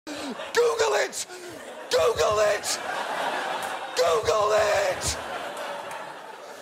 GOOGLE IT Sound Effect google it meme sound effect from a TV show yelling google it google it sound effect
GOOGLE-IT-Sound-Effect.mp3